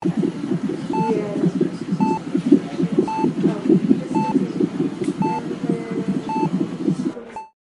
Free SFX sound effect: Life Support.